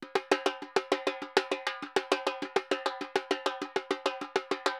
Repique Candombe 100_1.wav